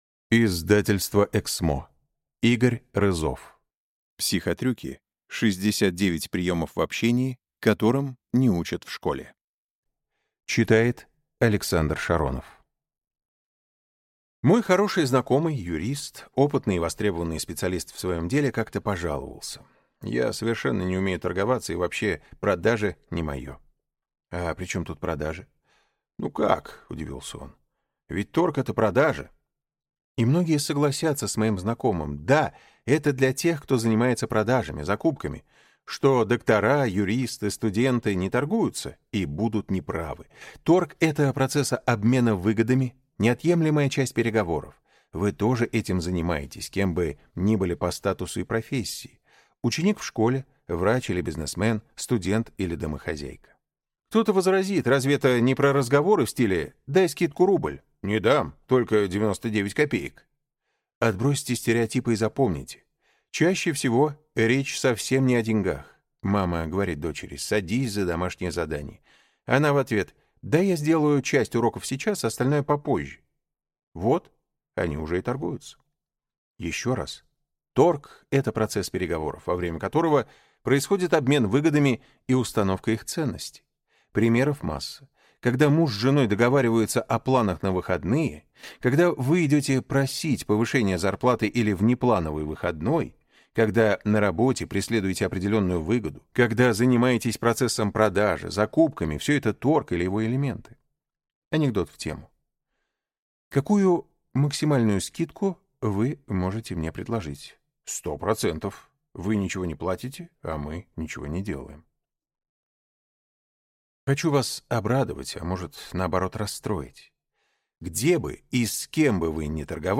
Аудиокнига Психотрюки. 69 приемов в общении, которым не учат в школе | Библиотека аудиокниг